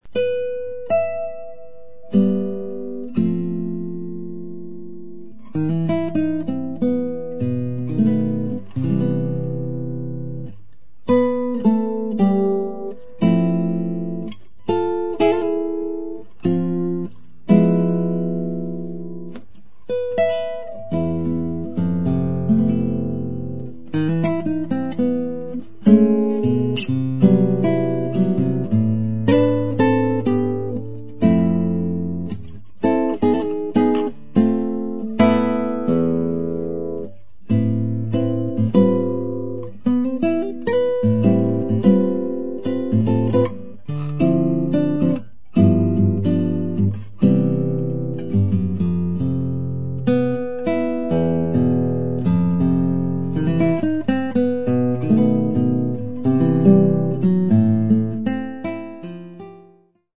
acoustic guitar